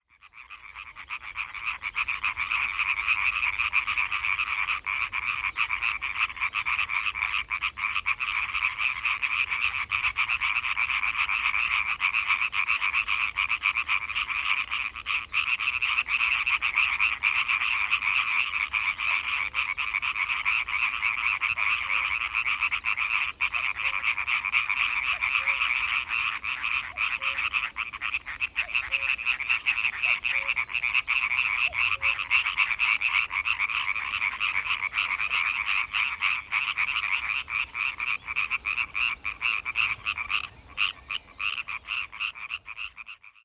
boomkikker
🔭 Wetenschappelijk: Hyla arborea
♪ kwaken
Fochteloërveen.
boomkikker.mp3